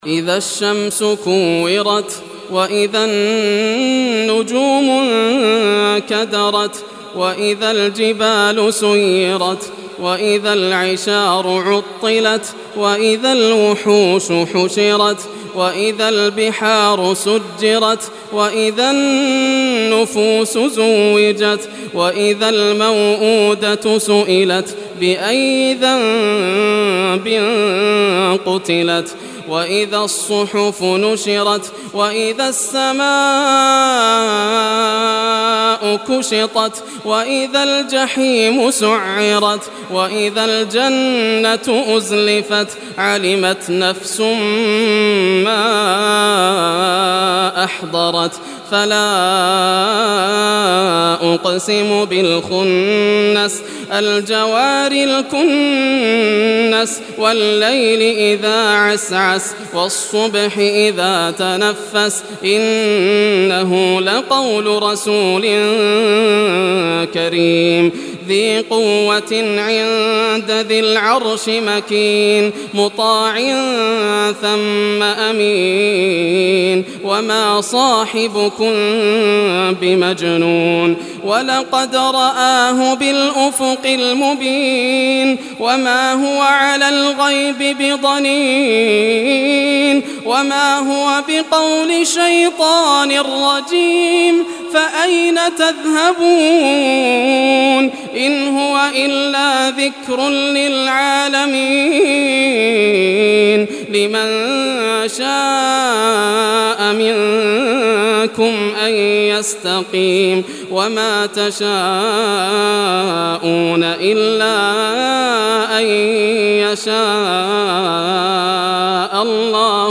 Surah Takwir Recitation by Yasser al Dosari
Surah Takwir, listen or play online mp3 tilawat / recitation in Arabic in the beautiful voice of Sheikh Yasser al Dosari.
81-surah-takwir.mp3